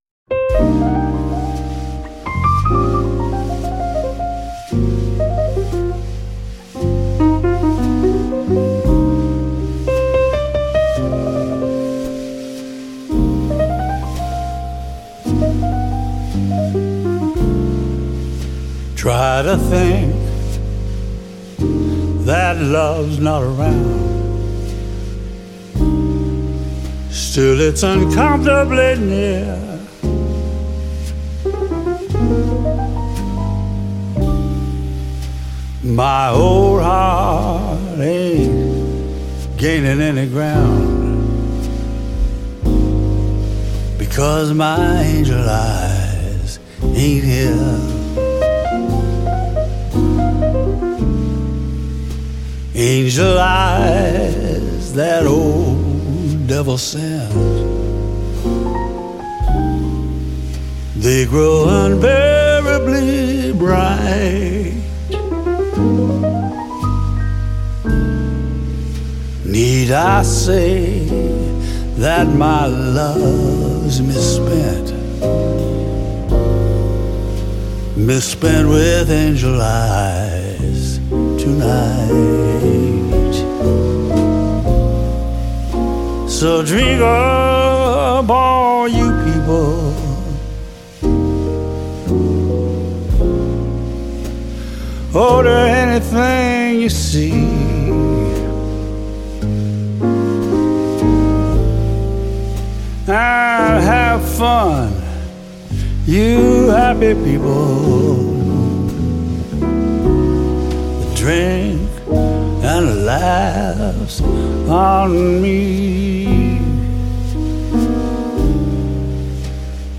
фортепианный джаз